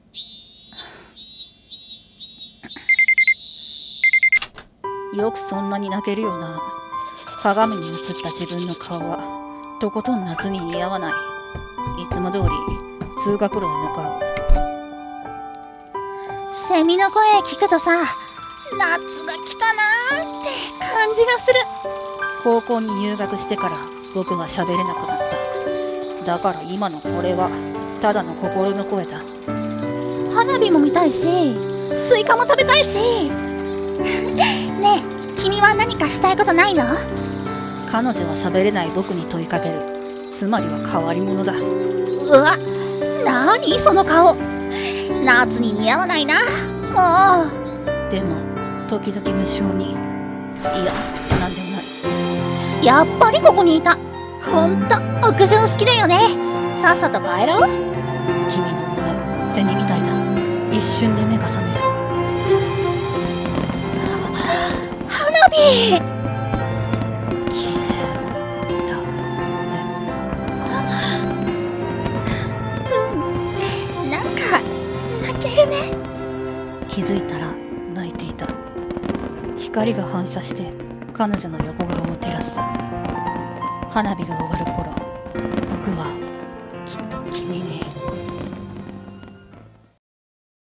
【声劇】夏に鳴いたのは、